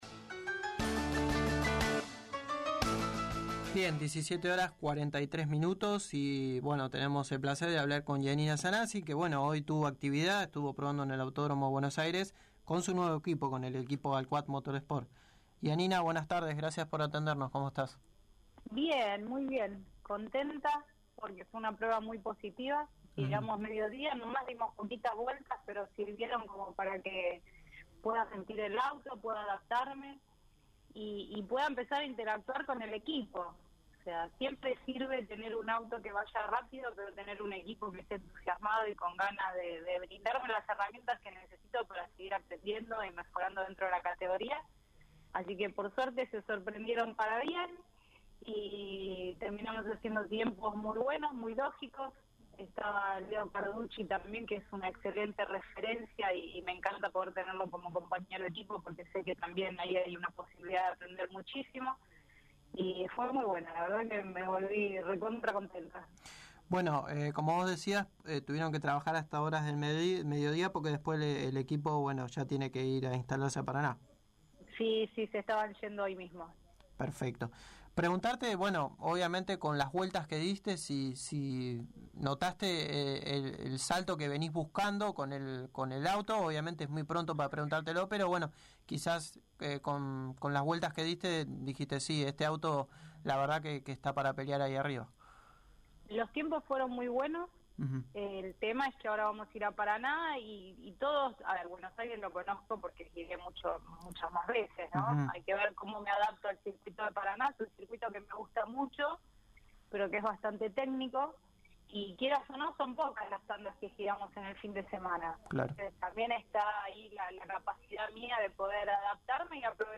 en una entrevista realizada en nuestro programa